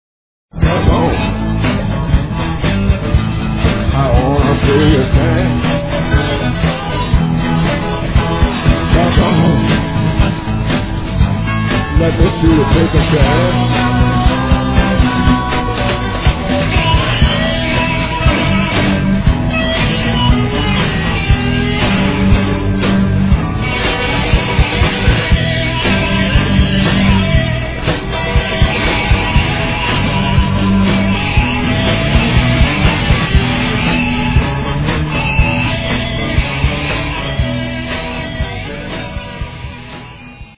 BLUES
Me (steel guitar). Live from Brno [2002].